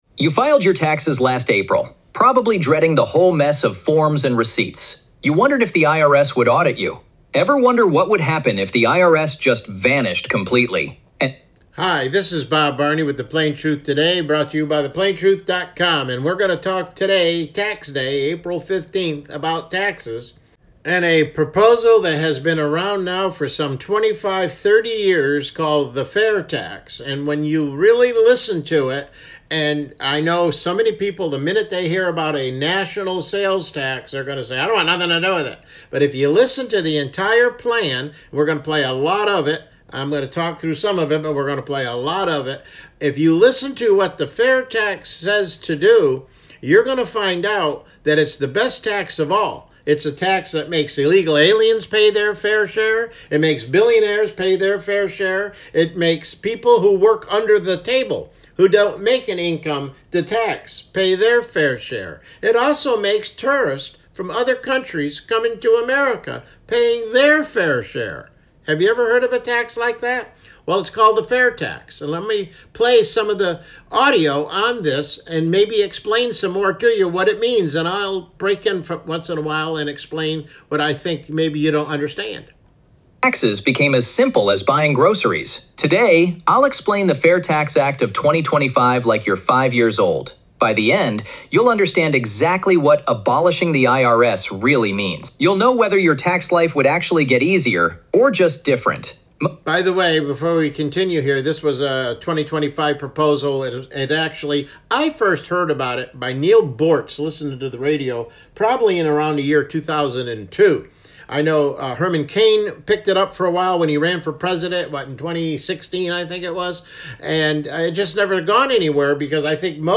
CLICK HERE TO LISTEN TO THE PLAIN TRUTH TODAY MIDDAY BROADCAST: The Fair Tax | Part 1